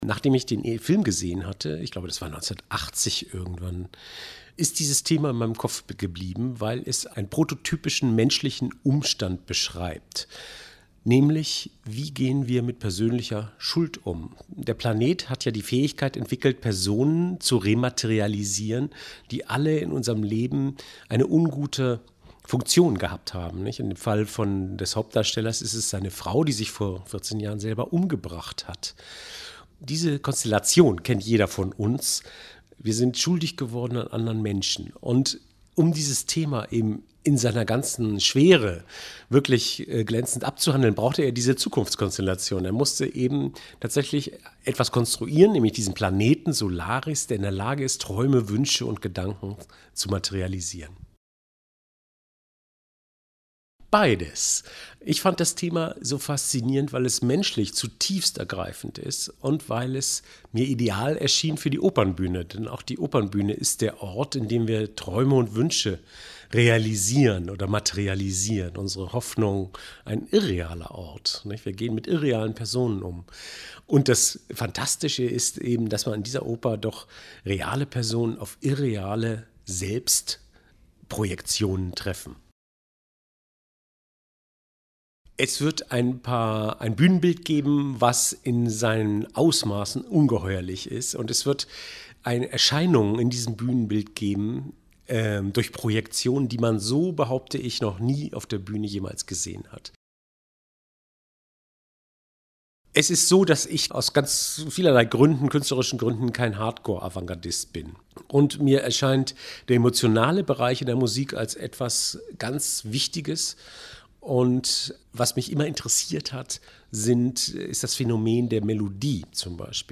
Interview mit Detlev Glanert, Komponist von Solaris und Nijinskys Tagebuch